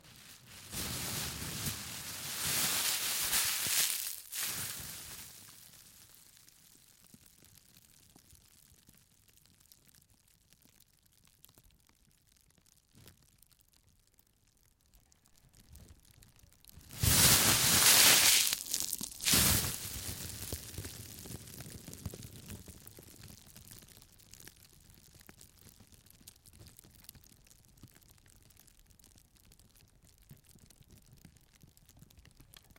描述：稍微碾碎一个薄塑料袋，让它每次都休息，以获得拉长的噼啪声。 嘎吱嘎吱/裂纹/嘎吱嘎吱/裂纹
Tag: 塑料 裂纹 碾碎 噼啪